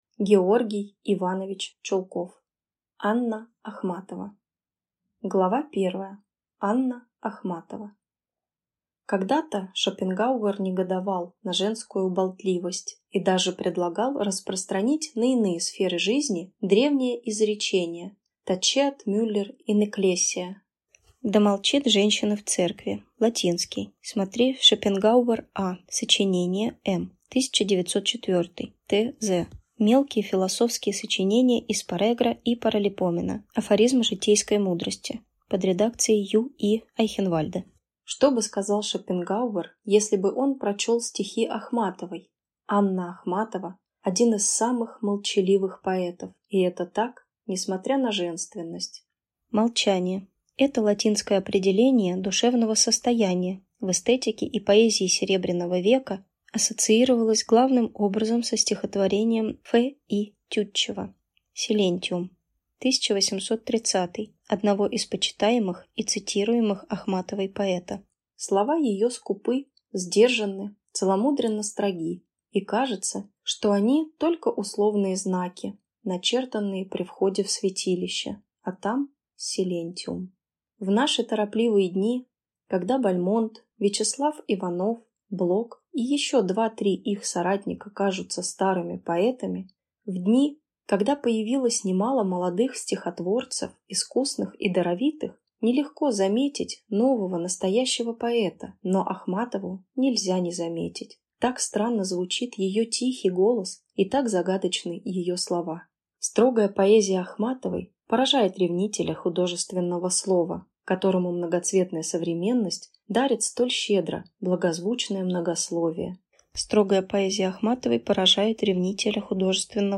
Аудиокнига Анна Ахматова | Библиотека аудиокниг